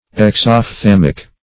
Search Result for " exophthalmic" : The Collaborative International Dictionary of English v.0.48: Exophthalmic \Ex`oph*thal"mic\, a. Of or pertaining to, or characterized by, exophthalmia.